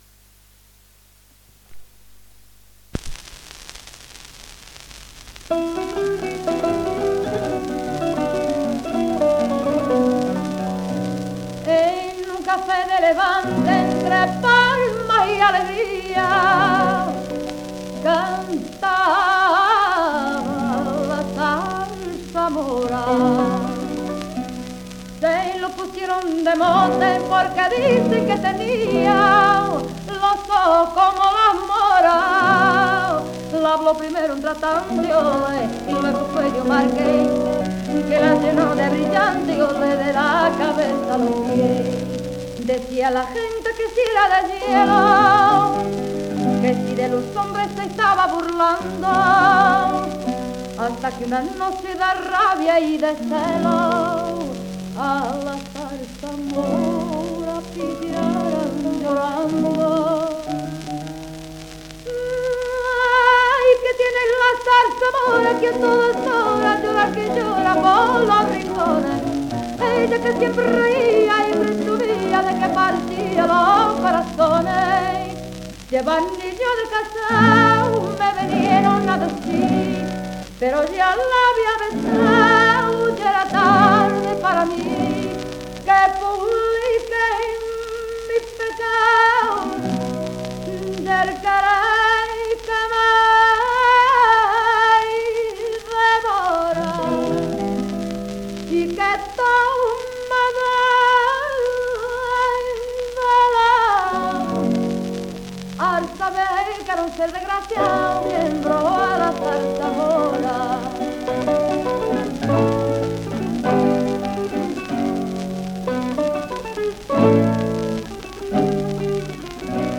inetmd-fcsh-ifpxx-mntd-audio-salsa_mora_espanhol-845.mp3